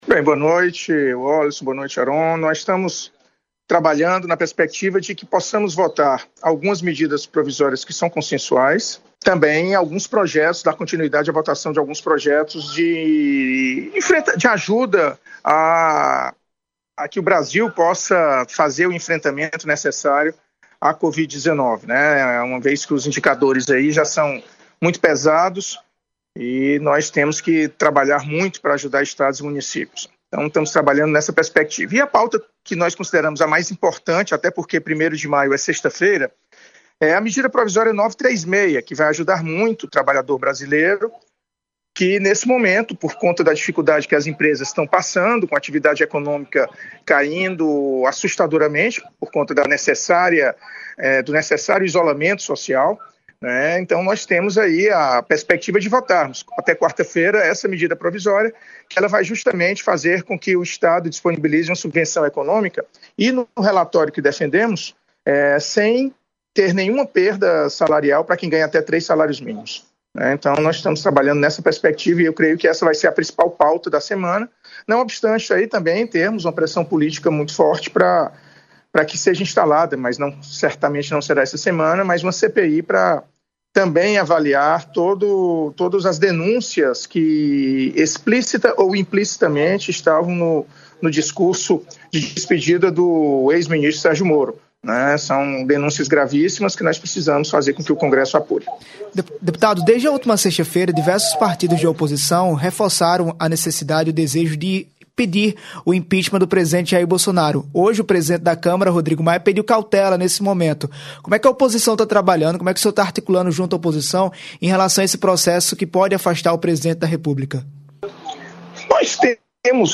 Foi hoje em entrevista ao Hora H, programa que vai ao ar de segunda à sexta, às 18h, na Rádio Pop FM 89,3, em João Pessoa, e na Rede Mais Rádio e em 14 emissoras nas principais regiões da Paraíba.